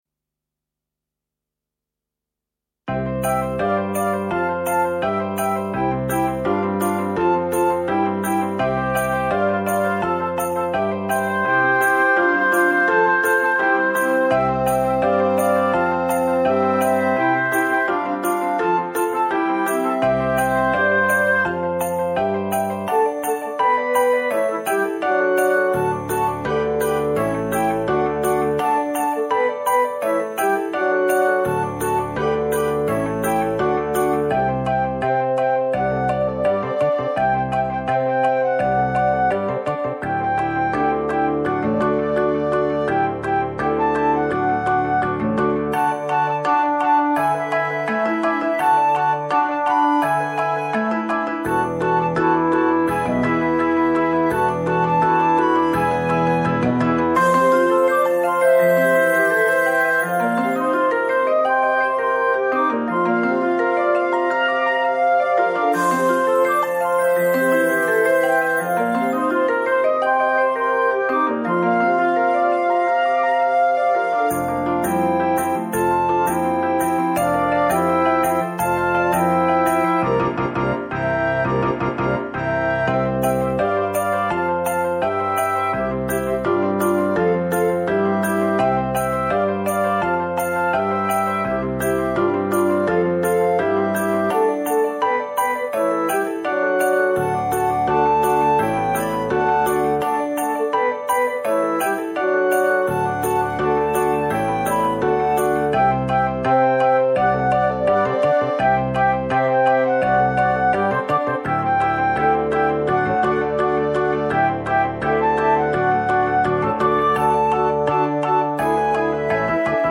Vocal with Piano
Songs can be sung in unison or split into two
The album is produce for piano and voice.
sparkling backing / practice tracks.